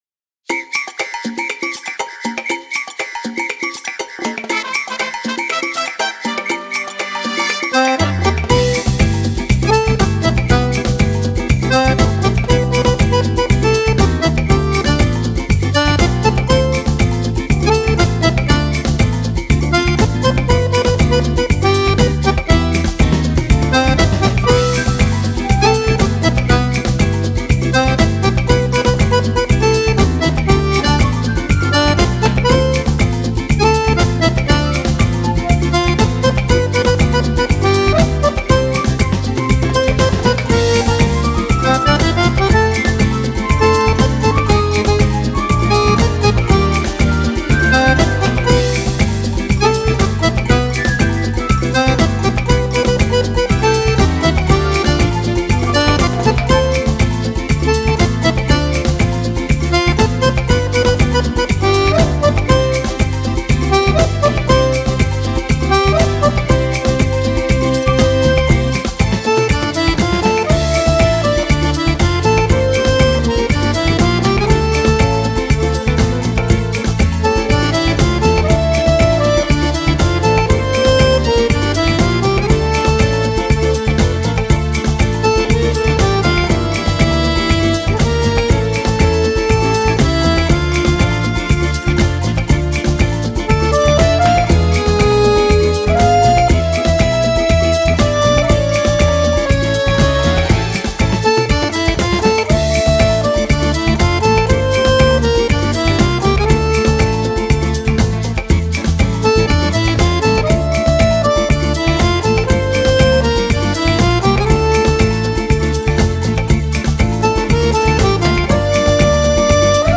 samba-mix-medley-samba